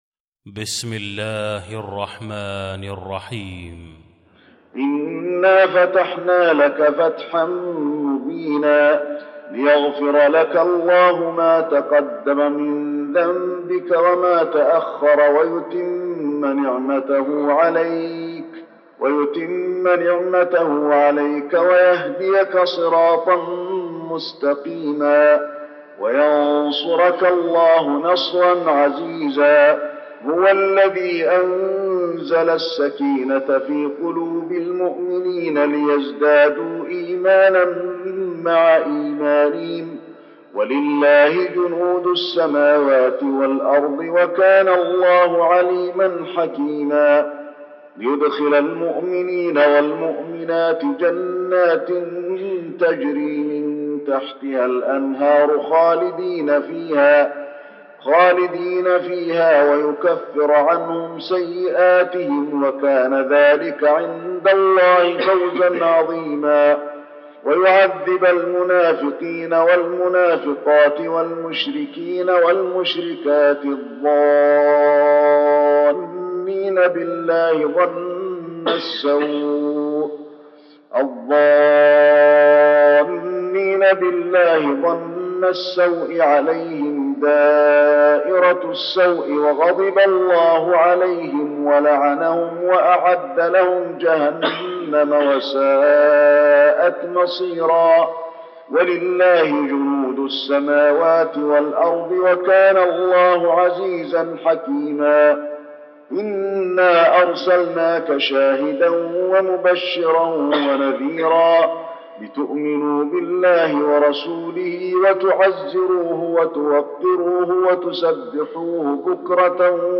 المكان: المسجد النبوي الفتح